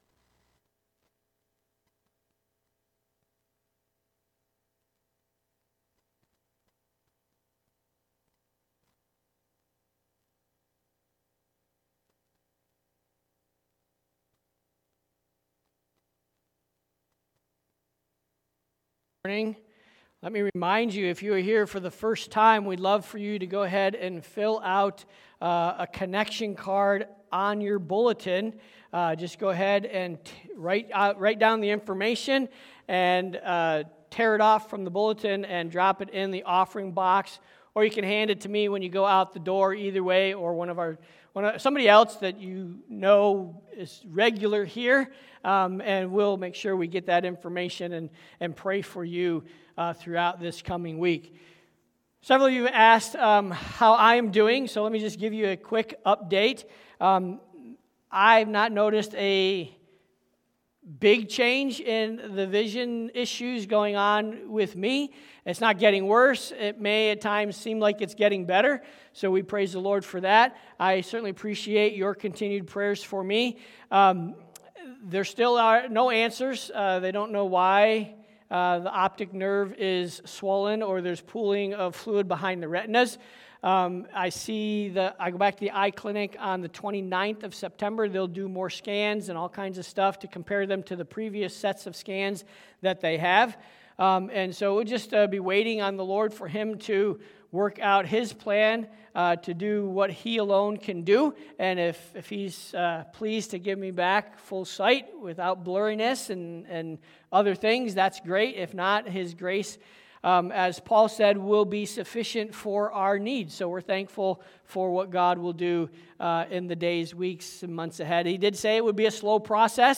Sermons by CBCP